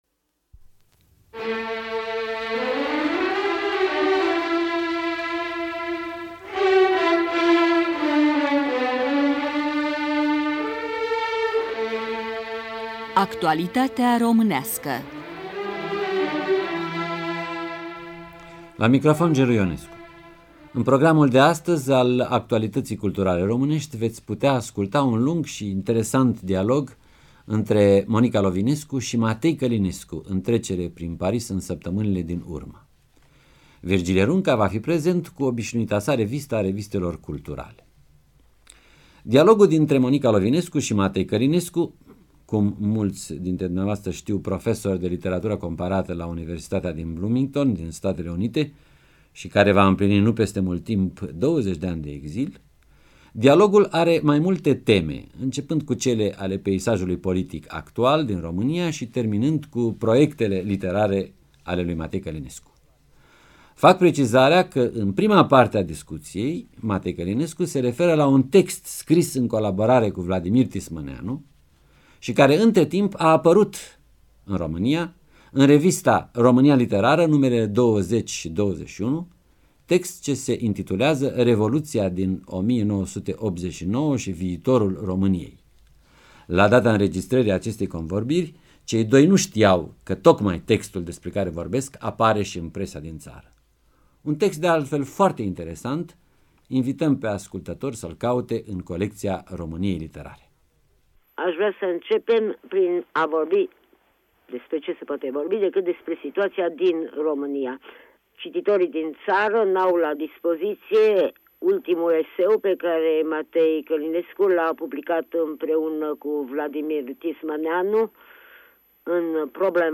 8.06.91 - Voci în eter: Monica Lovinescu în dialog cu Matei Călinescu
O discuție pe teme politice și literare românești avîndu-l ca invitat pe profesorul Matei Călinescu (1934-2009).